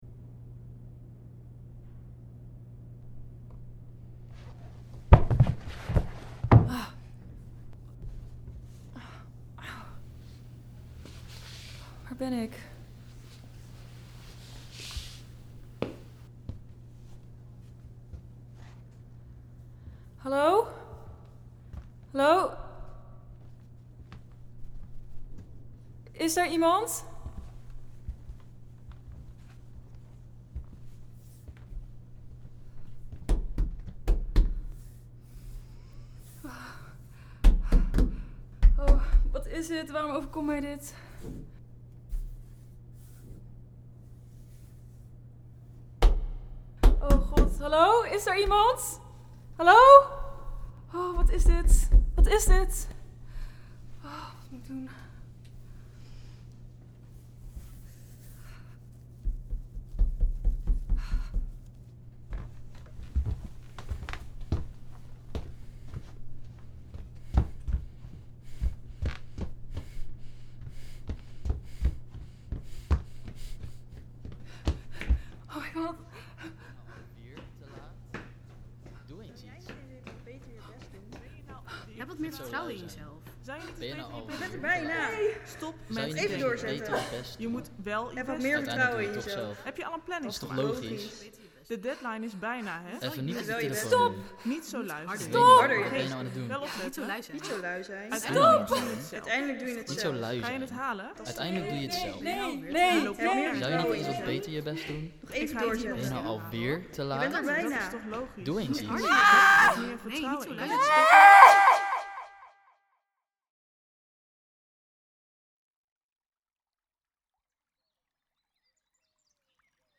De student beluisterd het audioverhaal.
Het audioverhaal eindigt met een korte stilte, en het geluid van pen en papier.
Deze spanning wordt opgebouwd in het verhaal door afwisseling van stilte en galmende geluiden. Trails en failure ontstaan wanneer verschillende stemmen de hoofdpersoon overvallen en ze deze uit pure paniek probeert weg te schreeuwen. Ze valt flauw, wanneer ze daarna op staat in de stilte wordt de aandacht getrokken naar het geluid van een stromend beekje.
De helper zit op zijn gemak gitaar te spelen.